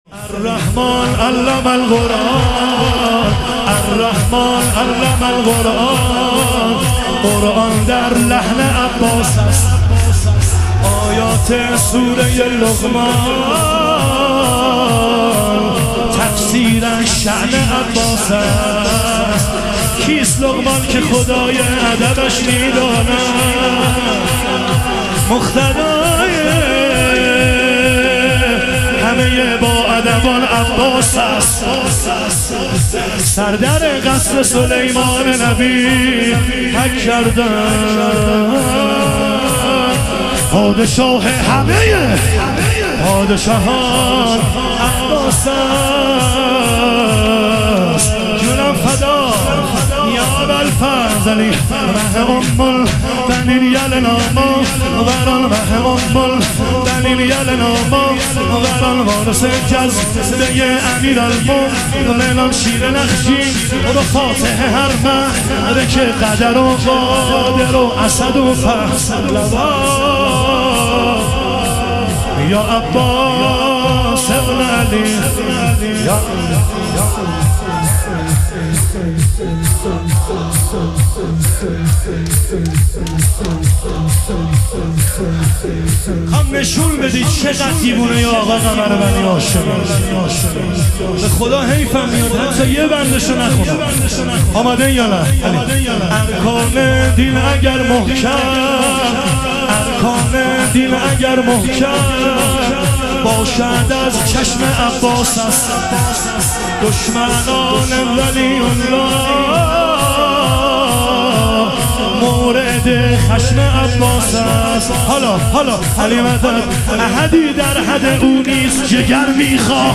شهادت حضرت خدیجه علیها سلام - شور